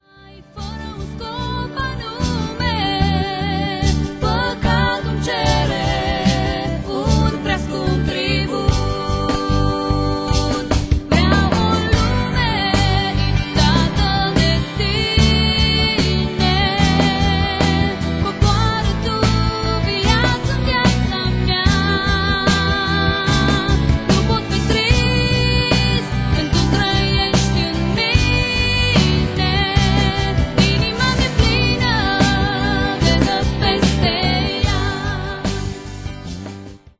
si vocile de exceptie.